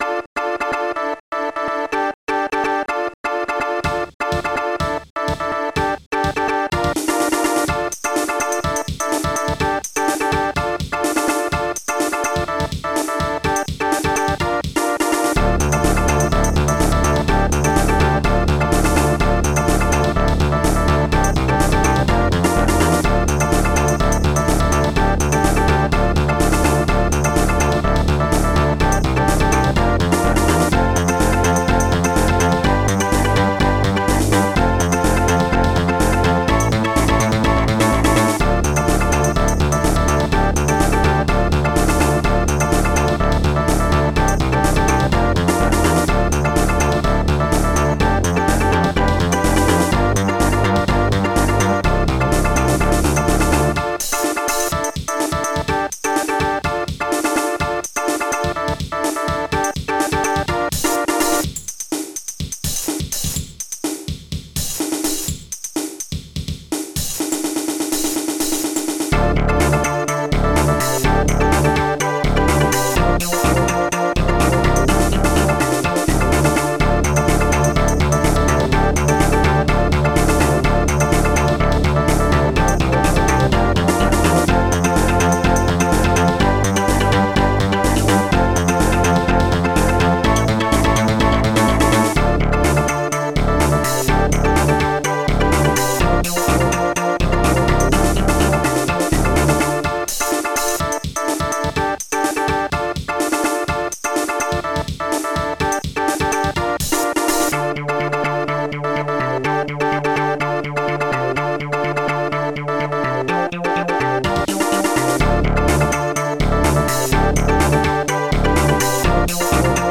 Protracker Module  |  1991-05-21  |  71KB  |  2 channels  |  44,100 sample rate  |  2 minutes, 33 seconds
Protracker and family
st-99:Snare6
st-99:hihat3
growl
st-99:bass5
st-99:chord1